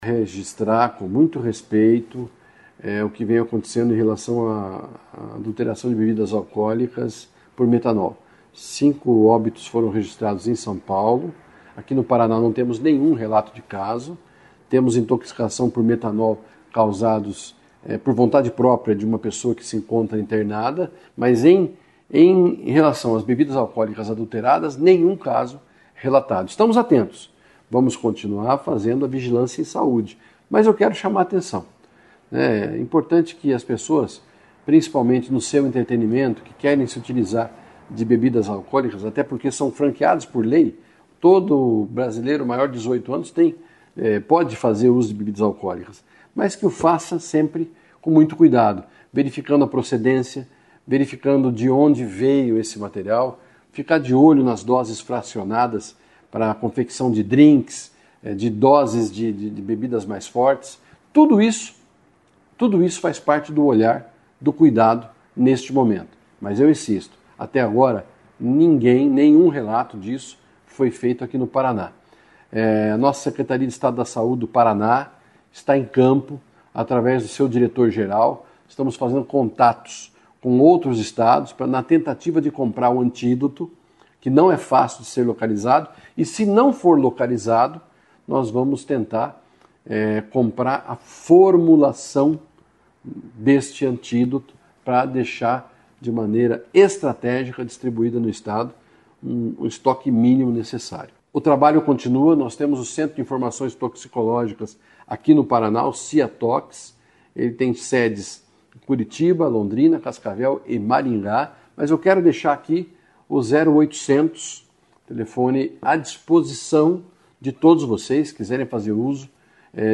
Sonora do secretário Estadual da Saúde, Beto Preto, sobre o monitoramento de eventuais situações de intoxicação por metanol | Governo do Estado do Paraná